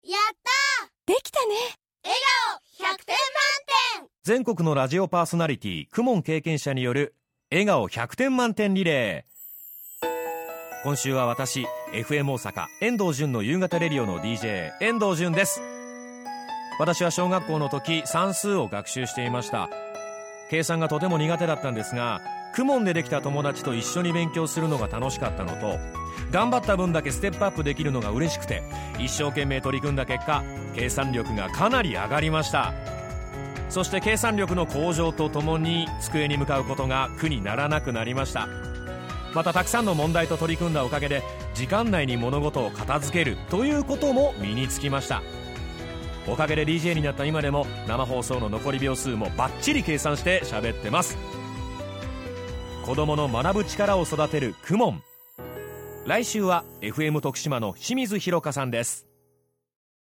「子どもの頃、KUMONやってました！」 「今、子どもが通っています！」･･･という全国のパーソナリティのリアルな声をお届けします。